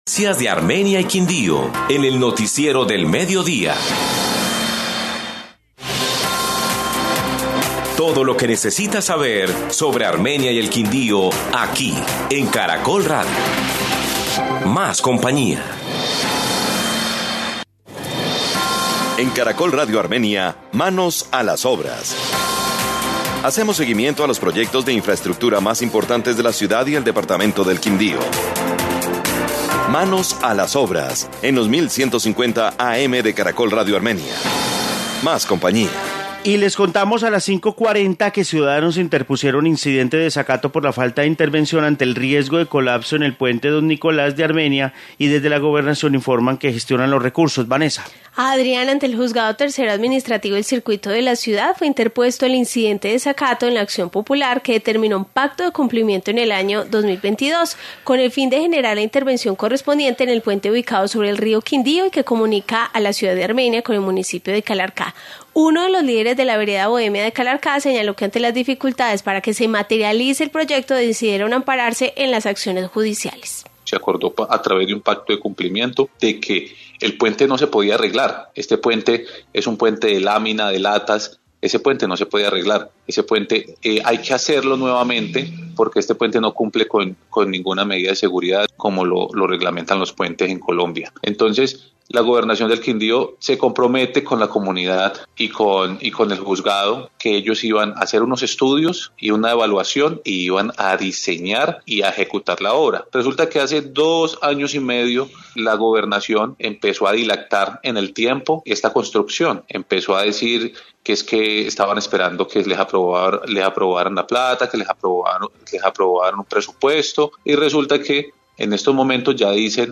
Informe puente entre Armenia y Calarcá